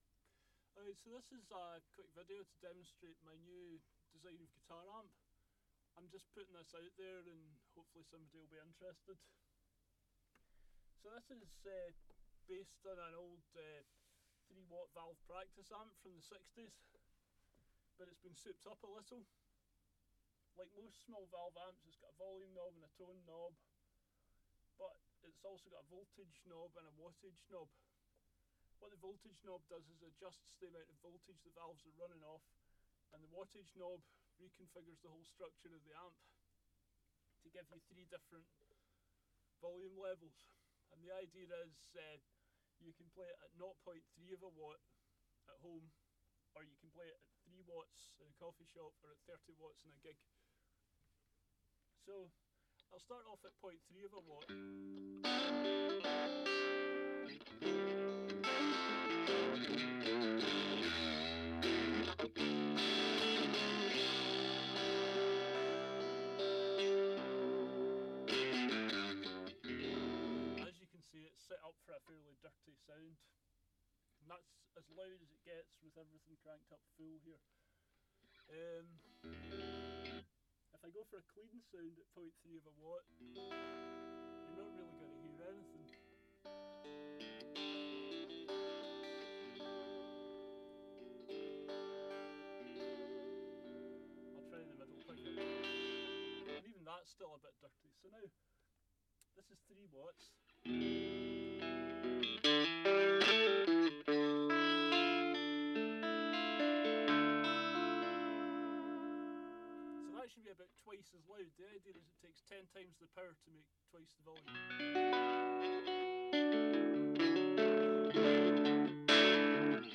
The result was amazingly successful.
High quality version of video soundtrack
This was recorded with no (dynamic range) compression, so the 0.3 watt setting is very quiet.
ninja-corvette-demo-hq.mp3